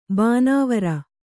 ♪ bānāvara